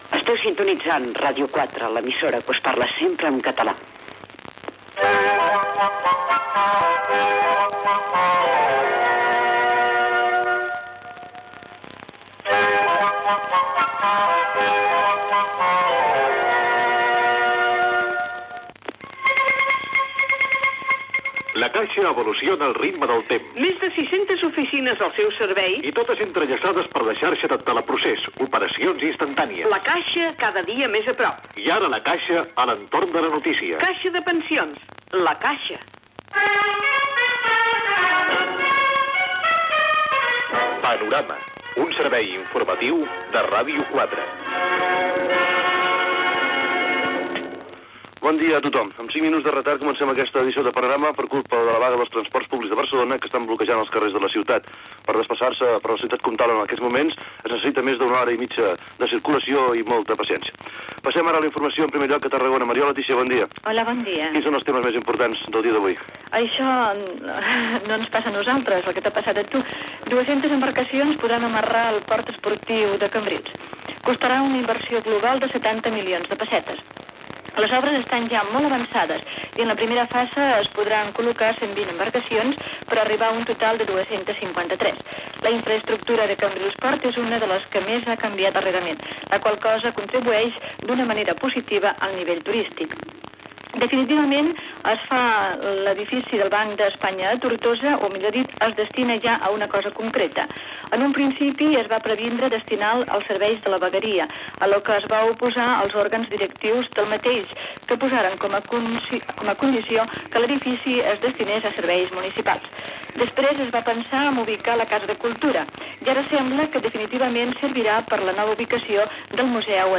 Informatiu
FM
Audició de Ràdio 4 per Ràdio Ebre de Tortosa que entre 1979 i 1981 hi connectava.